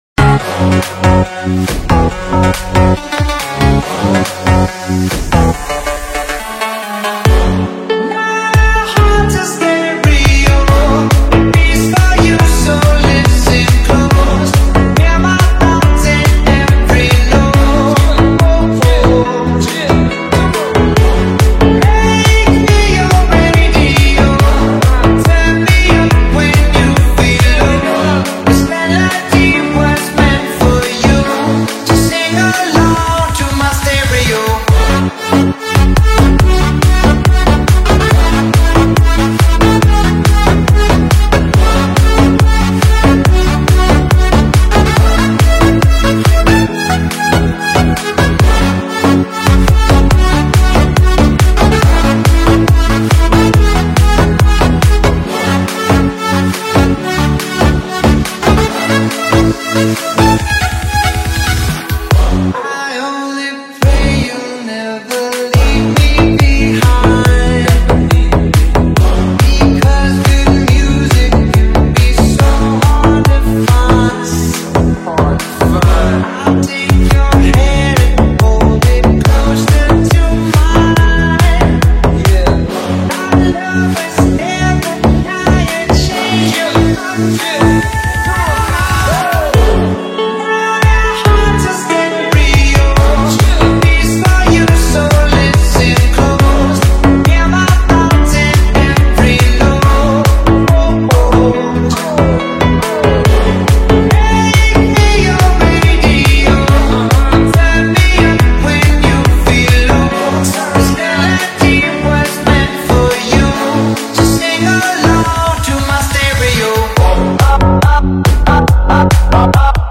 ریمیکس بیس دار پرانرژی